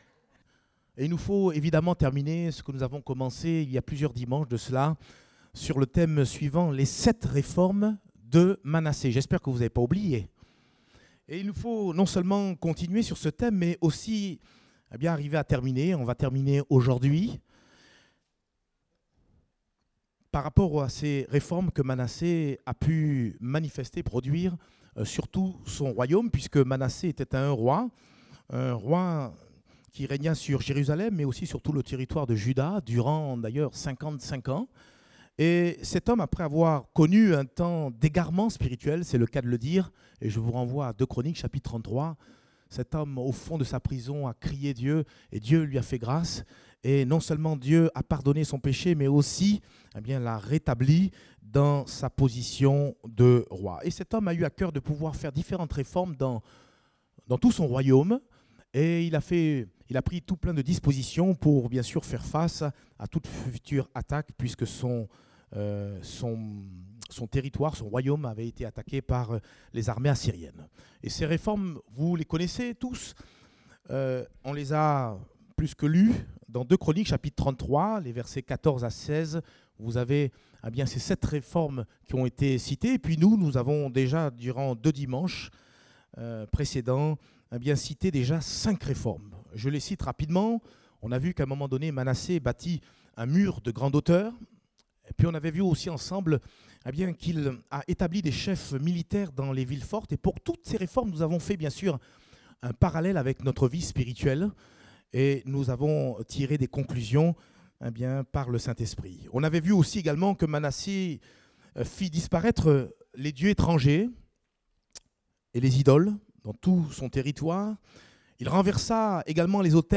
Date : 5 août 2018 (Culte Dominical)